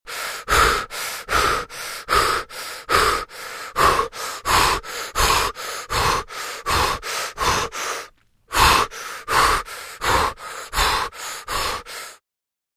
Звуки отдышки
Подборка включает разные варианты дыхания: учащенное, прерывистое, глубокое.
Мужское дыхание